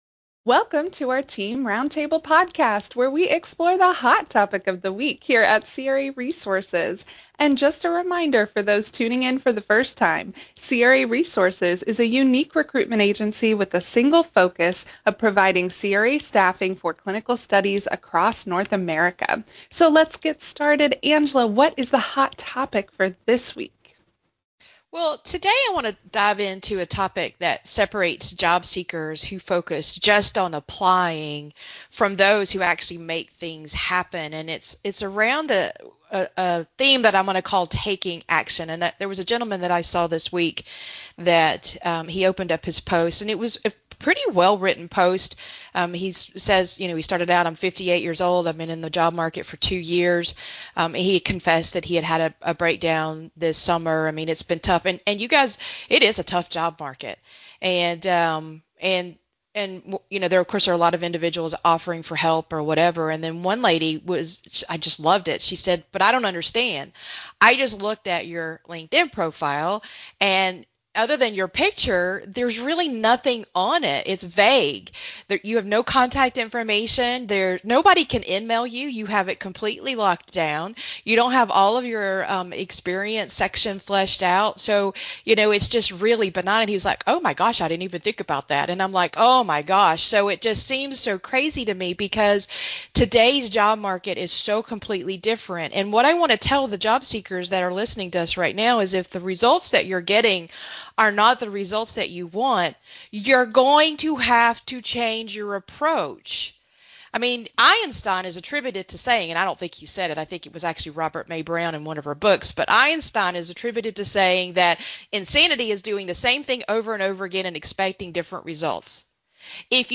Welcome to our latest roundtable podcast, where a team of career pros dives deep into the art and science of taking action in today’s job market.
This lively conversation tackles these questions and more, with insights you can start using immediately to change your career path.